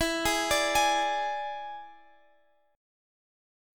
E7 Chord (page 5)
Listen to E7 strummed